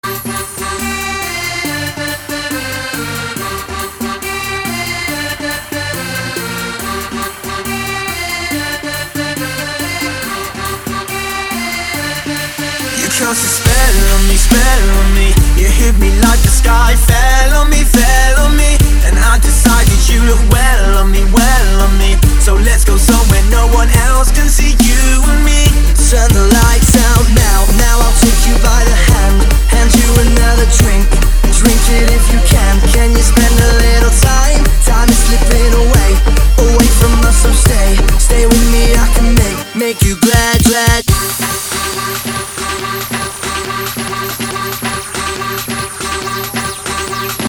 • Качество: 256, Stereo
поп
мужской вокал
Eurodance
танцевальная музыка
евродэнс
данс-поп